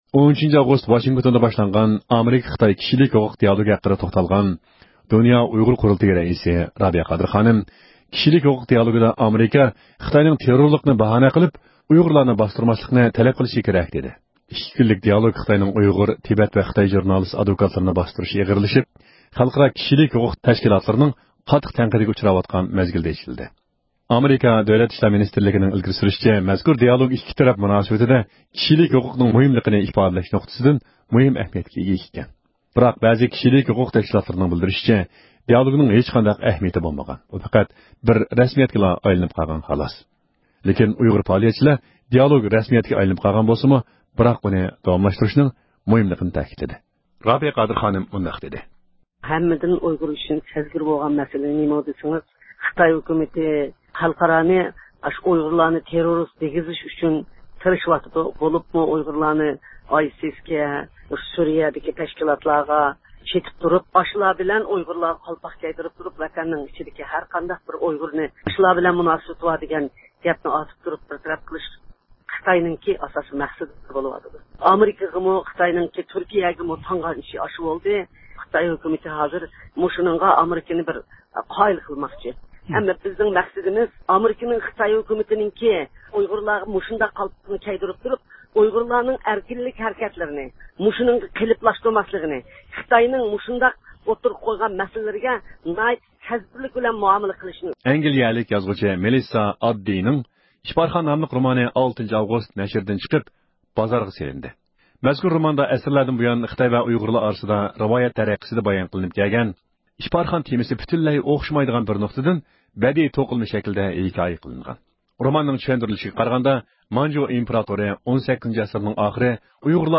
ھەپتىلىك خەۋەرلەر (8-ئاۋغۇستتىن 14-ئاۋغۇستقىچە) – ئۇيغۇر مىللى ھەركىتى